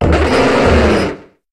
Cri de Bétochef dans Pokémon HOME.